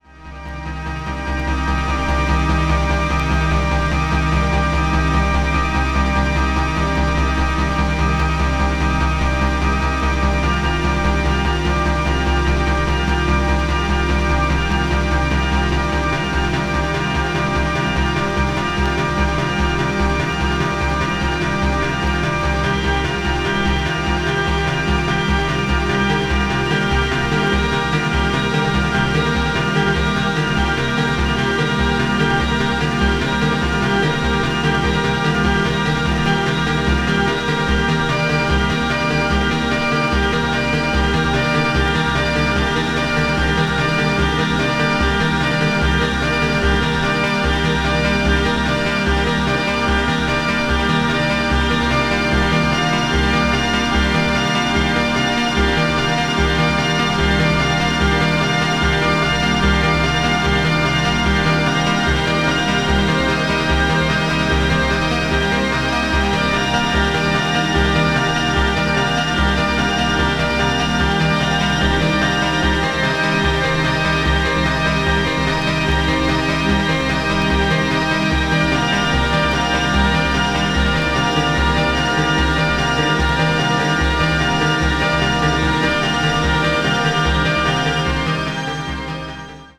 まさにベルリン・スクールな深層電子音楽。